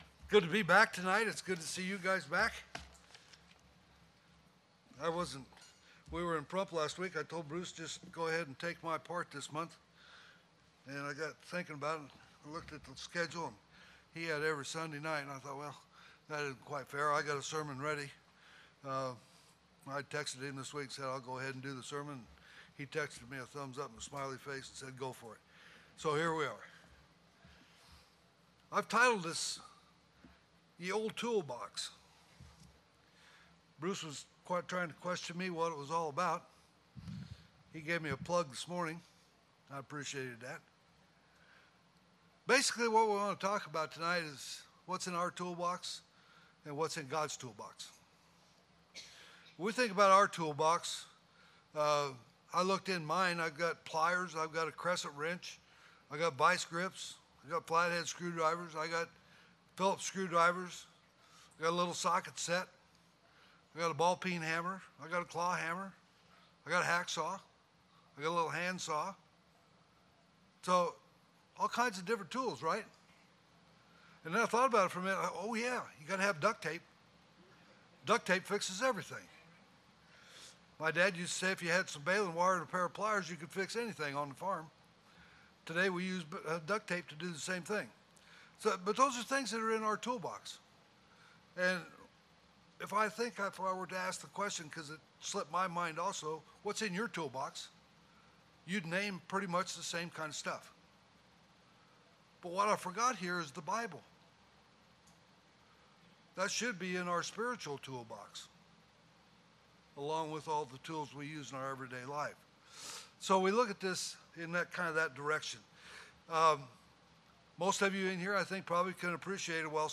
Sermons
PM Worship